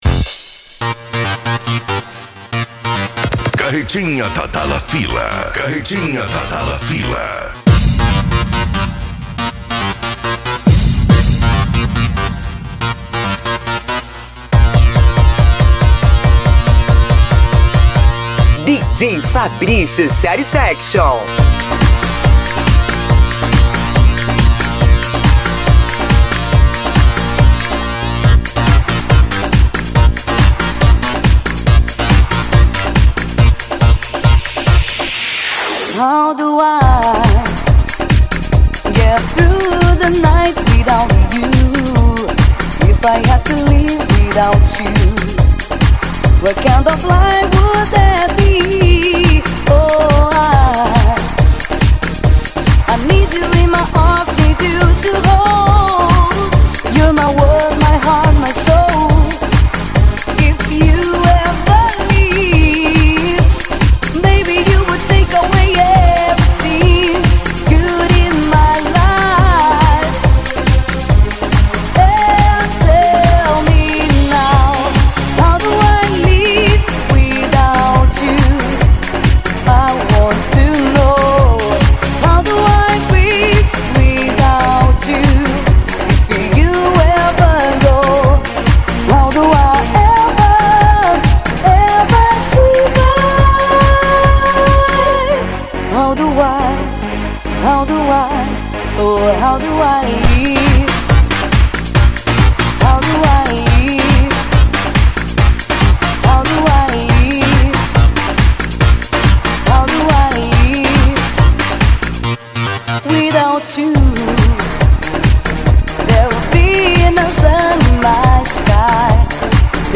PANCADÃO
Retro Music
SERTANEJO